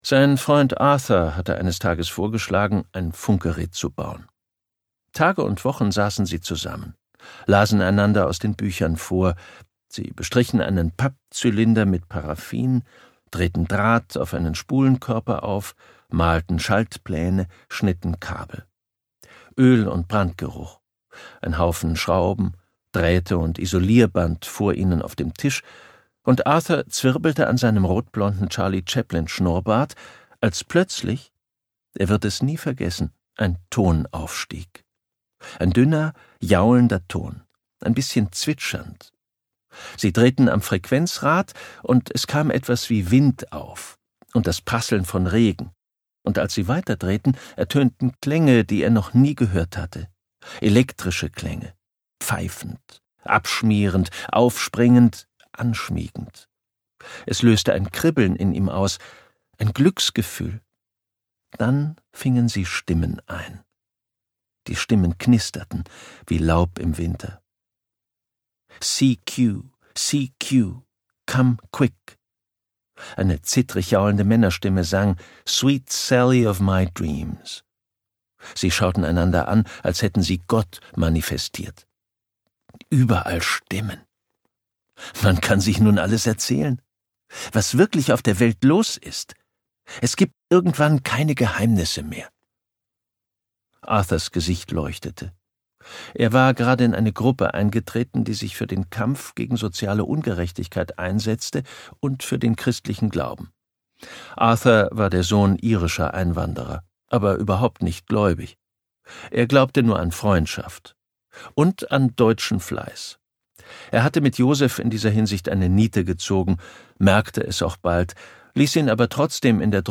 2020 | Leicht gekürzte Lesung